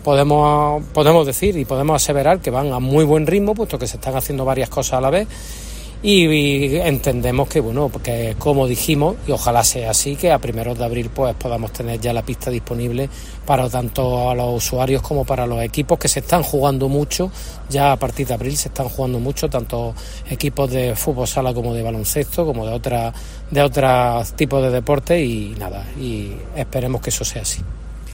José María Álvarez, concejal de Deportes del Ayuntamiento de Jaén